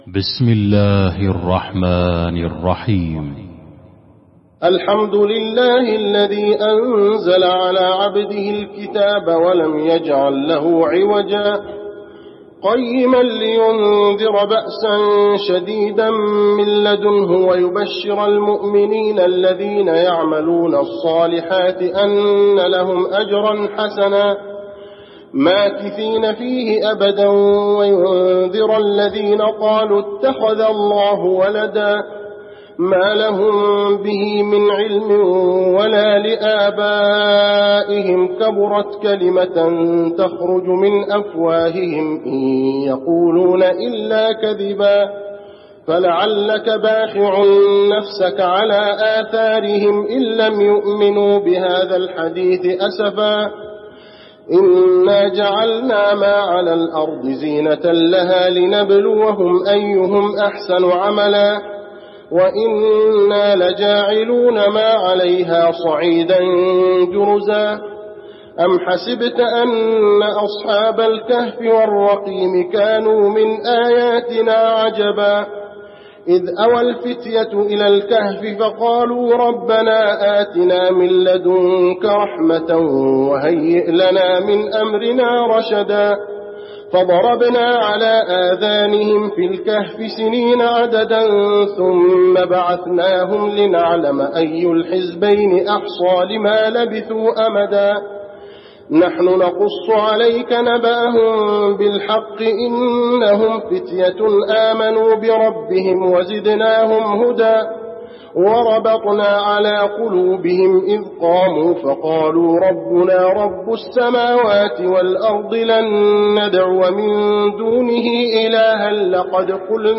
المكان: المسجد النبوي الكهف